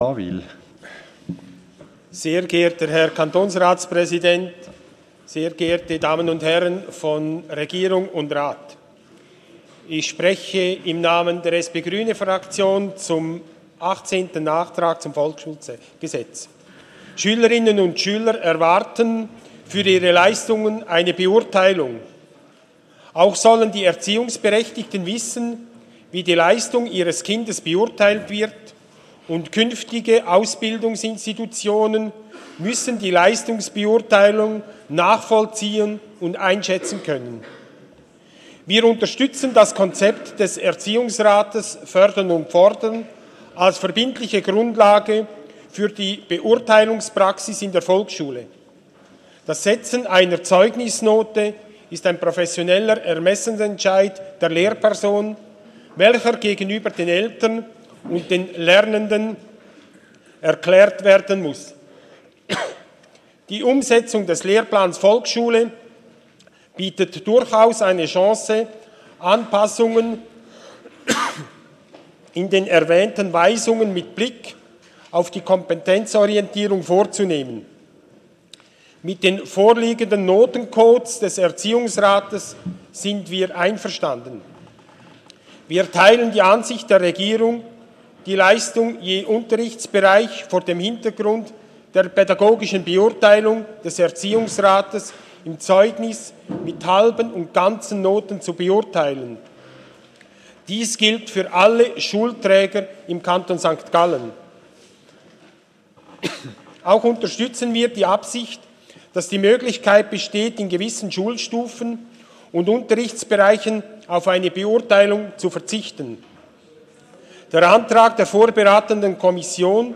25.4.2017Wortmeldung
Session des Kantonsrates vom 24. und 25. April 2017